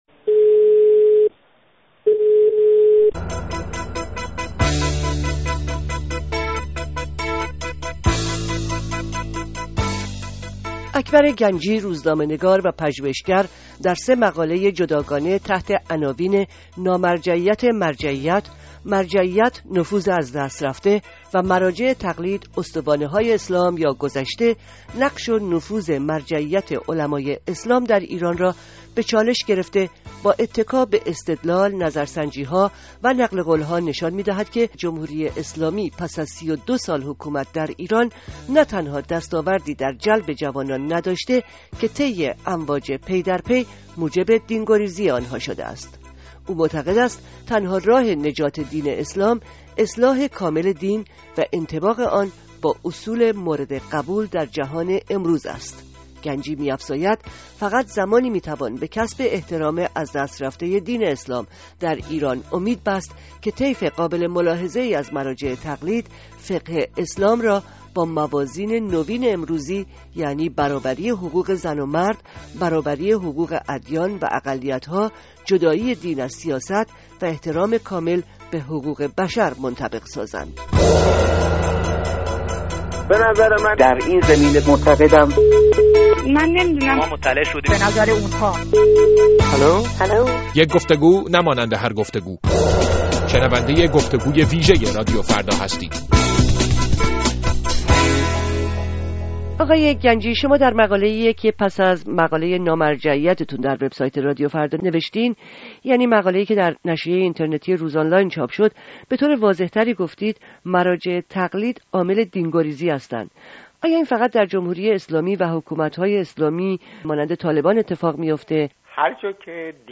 گفت‌وگوی ویژه رادیوفردا با اکبر گنجی درباره موقعیت و جایگاه مراجع در جامعه امروز ایران